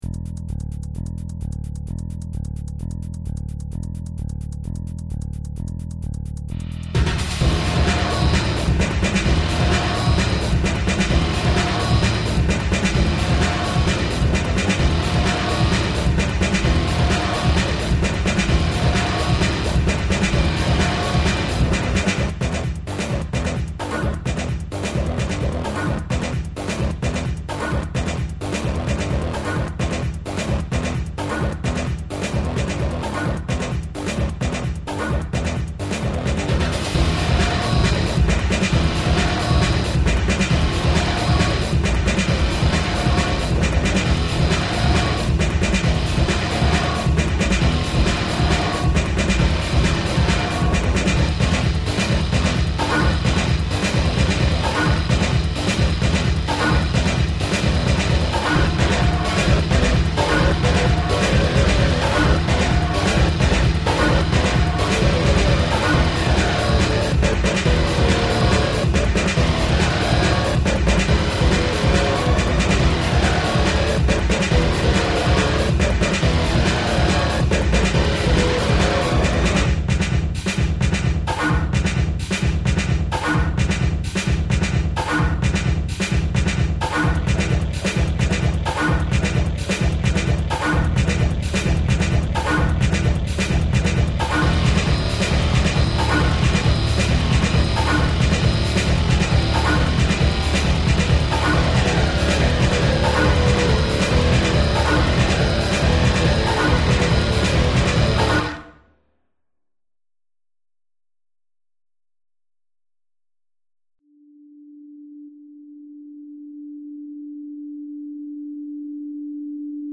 Recollections, remastered and reworked.
Overall? Dreamy.